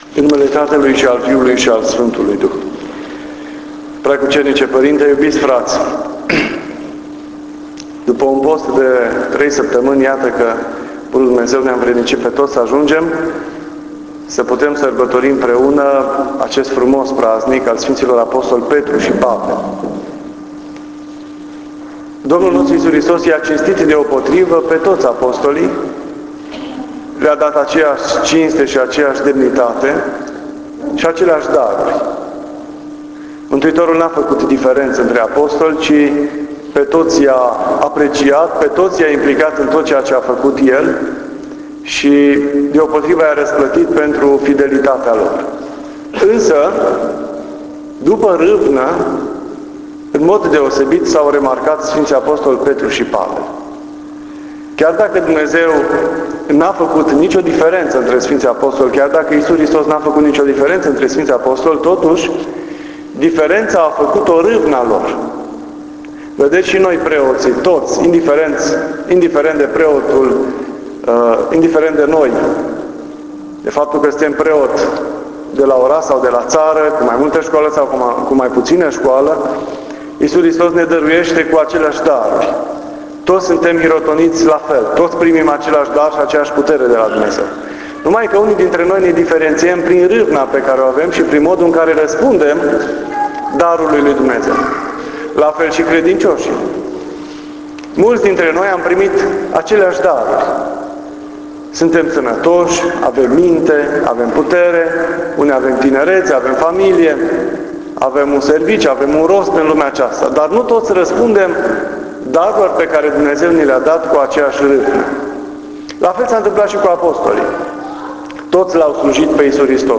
Cuvânt la Sf. Ap. Petru și Pavel. Despre convertirea Sf. Pavel (29 iunie 2017)